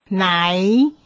hnaí